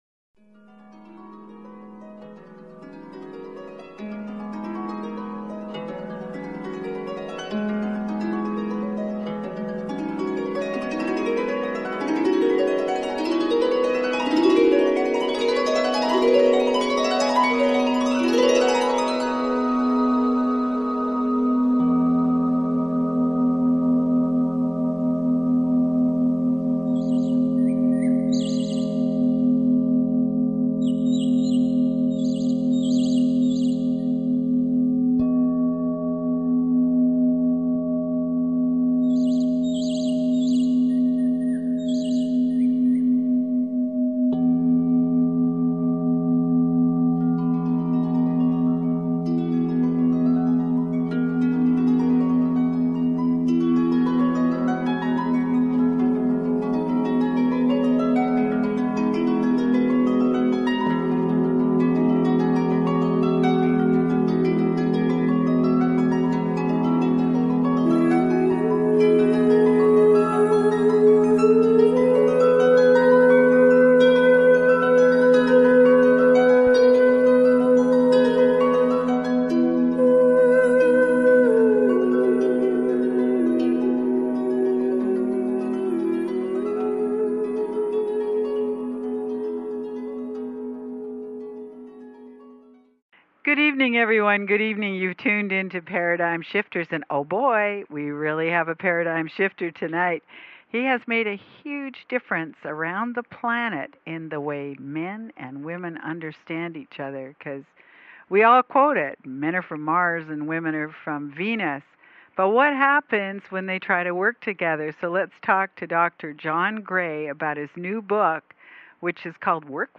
Talk Show Episode, Audio Podcast, Paradigm Shifters and with John Gray on , show guests , about John Gray, categorized as Variety
Paradigm Shifters interview with John Gray